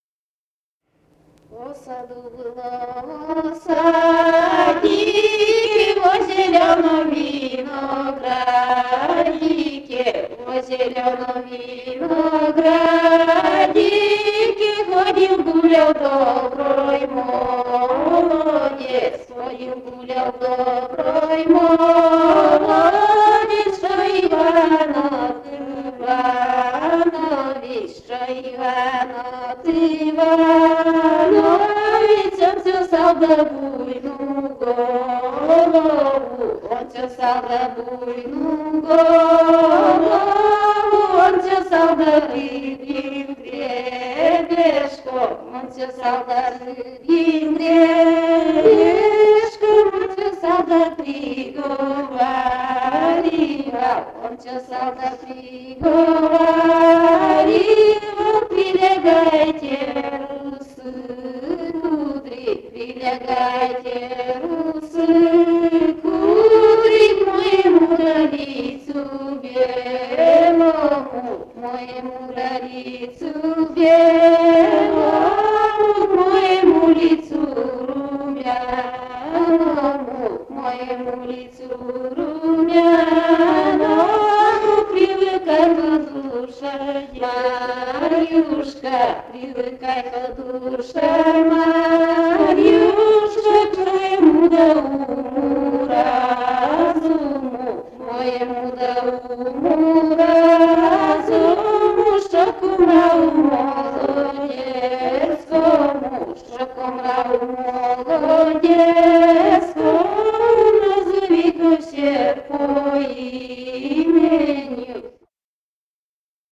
«Во саду было, во садике» (свадебная).